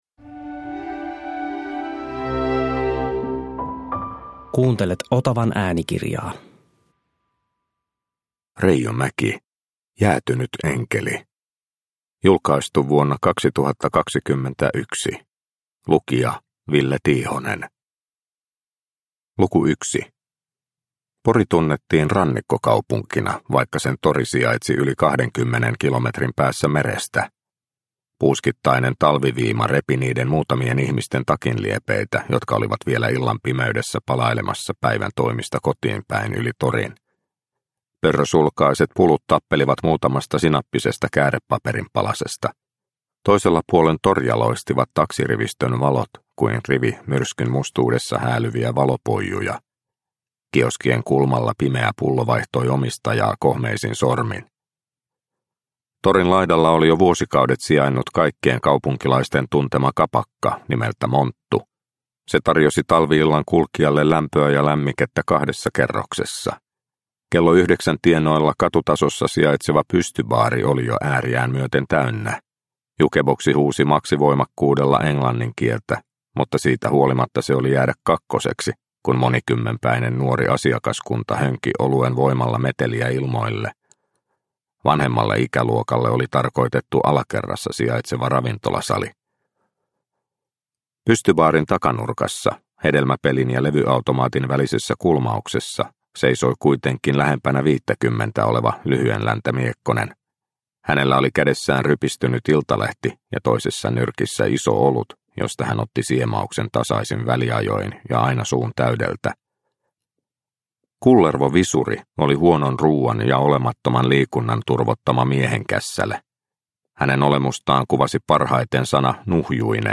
Jäätynyt enkeli – Ljudbok – Laddas ner